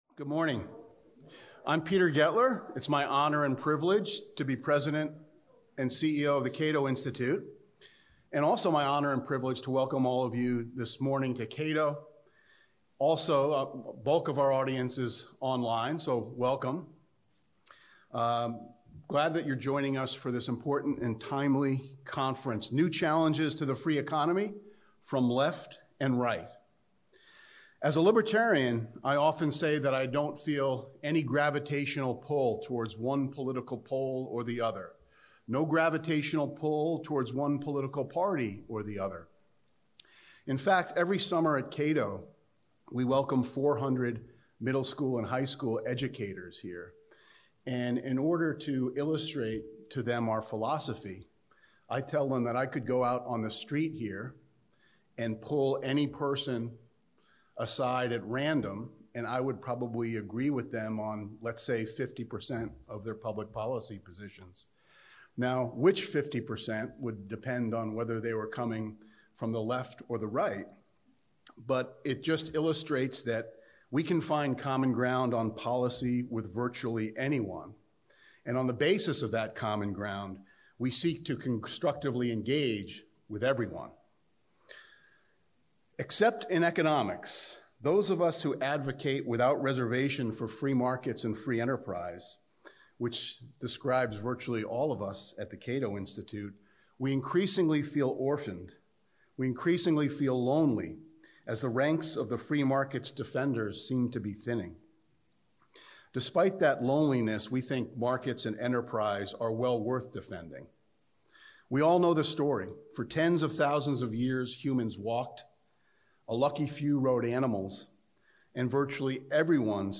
A Cato special conference